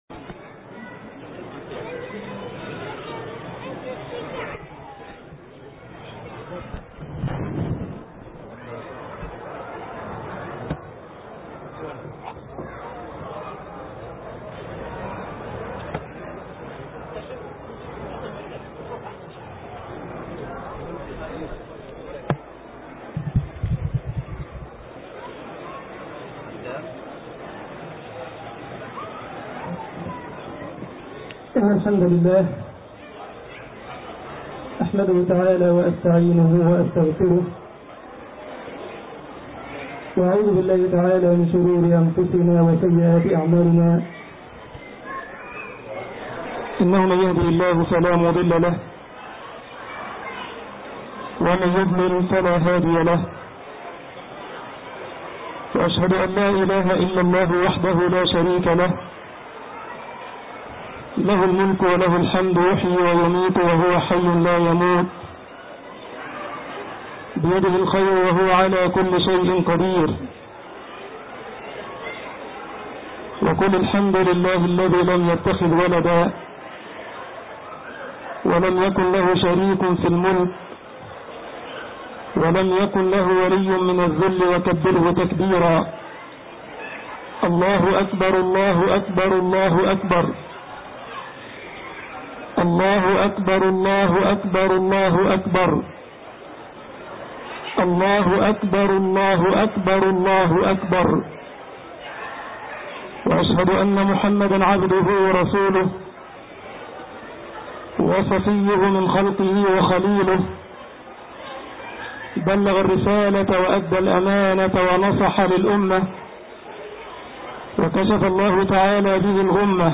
خطبة العيد